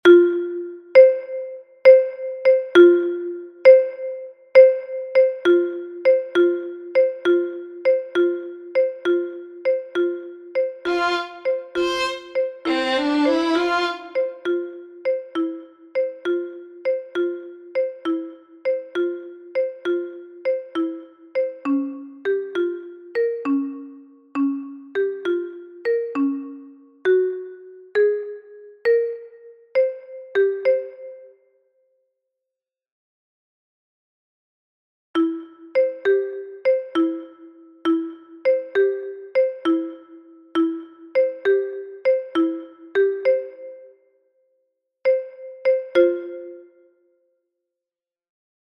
Here you have got the sound file with the silent bars.
Finally, here you have got the sound file corresponding to the accompaniment.
The_Sorcerers_Apprentice_silentbars_accomp.mp3